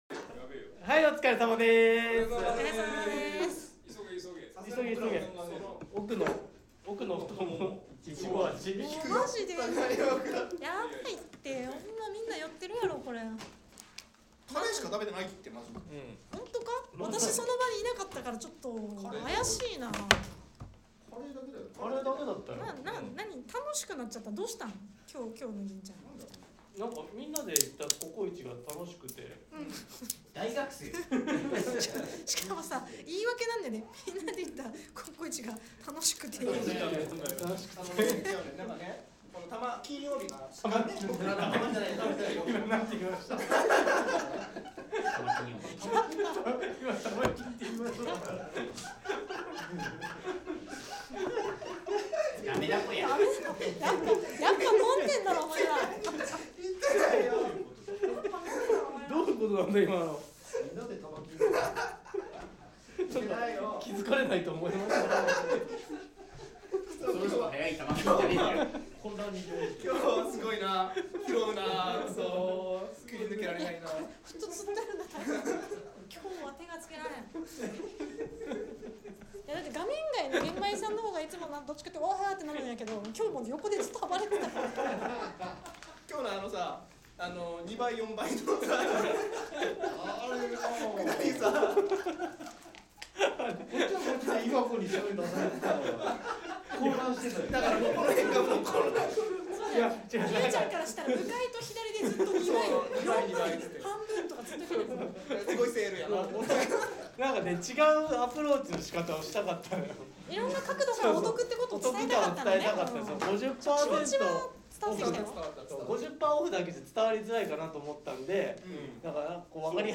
今回の楽屋裏トークもお聴き逃しなく！